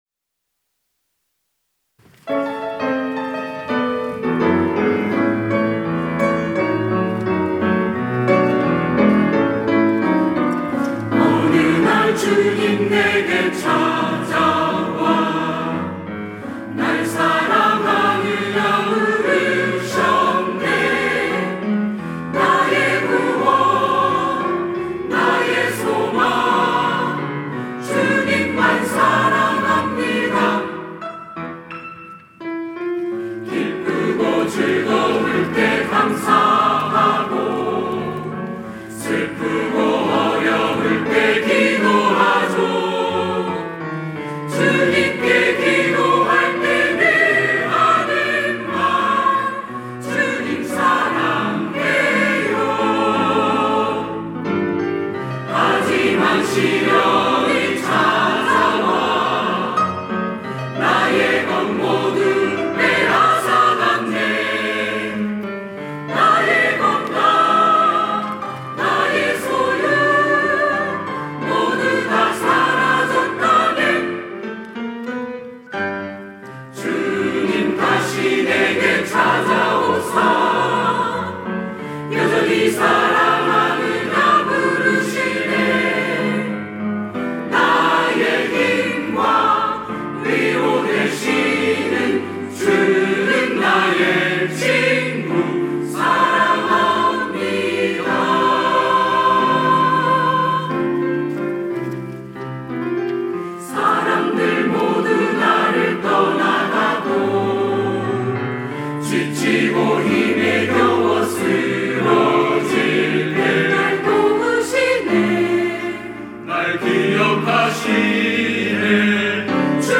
호산나(주일3부) - 주님 사랑해요
찬양대